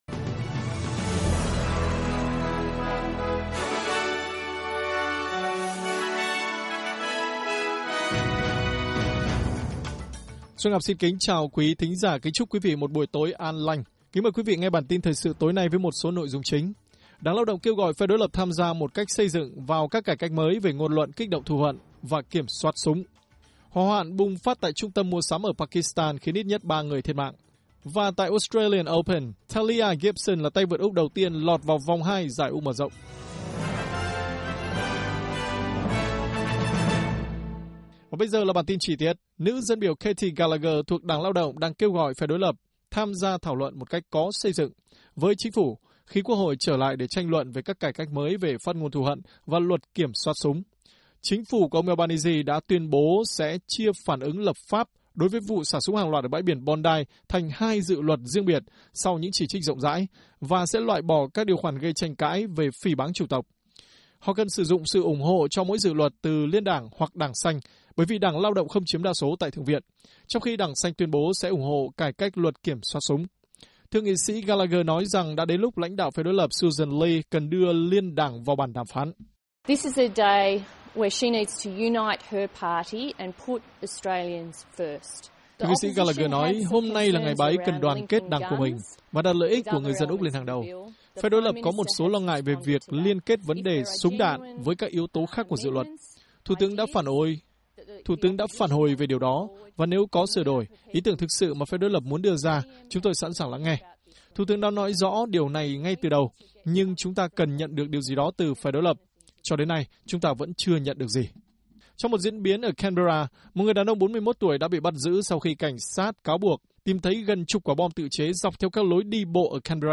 Bản tin của SBS Tiếng Việt sẽ có những nội dung chính.